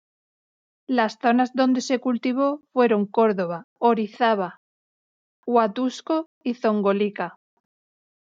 /ˈkoɾdoba/